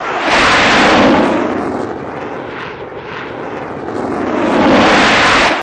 F-104 Middle, Rev Loop